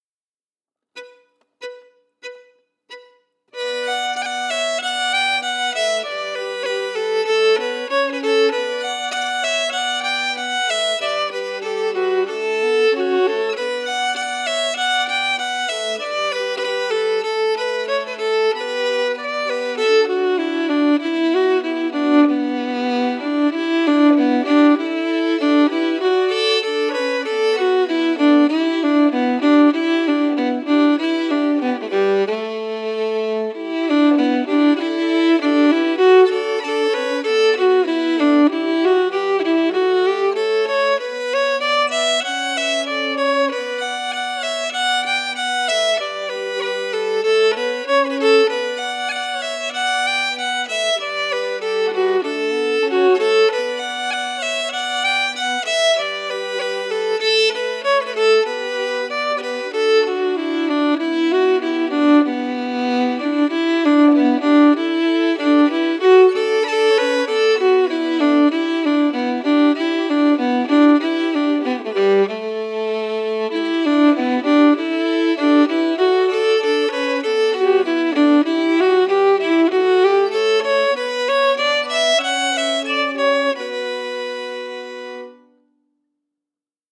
Key: Bm
Form: Reel
Melody emphasis